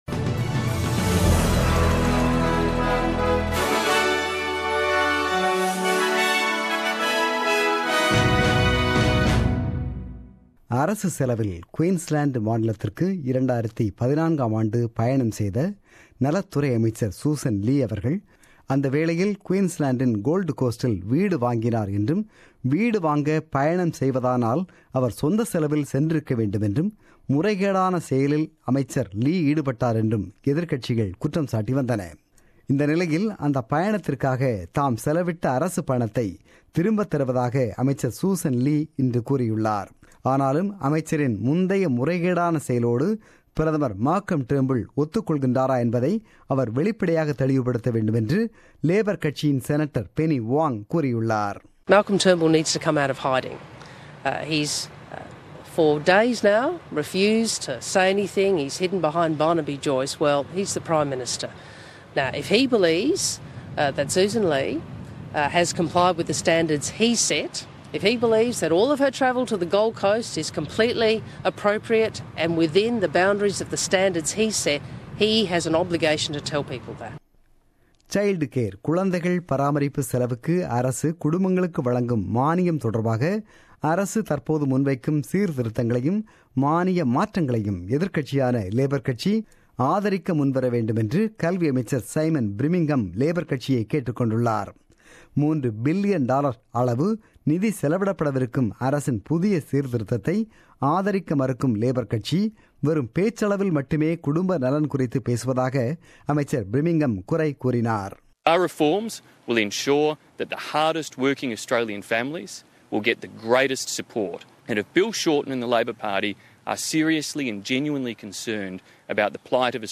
The news bulletin broadcasted on 8 January 2017 at 8pm.